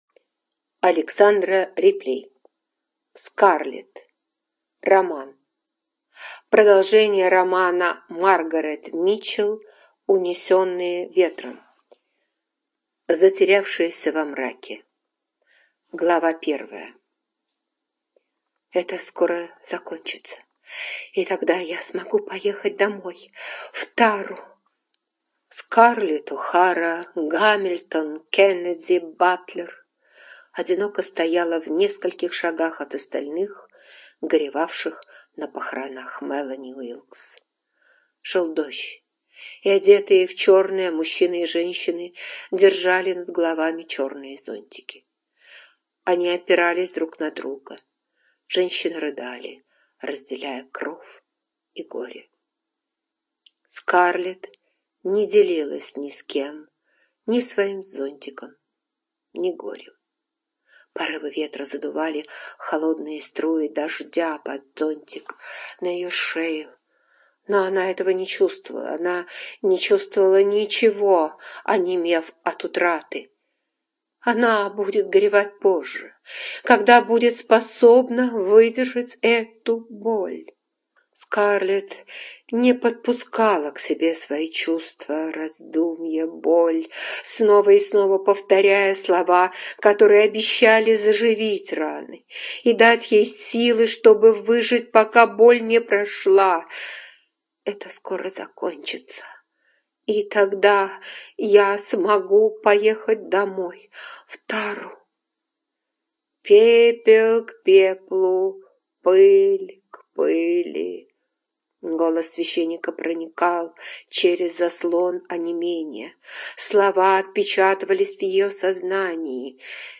ЖанрЛюбовная проза, Историческая проза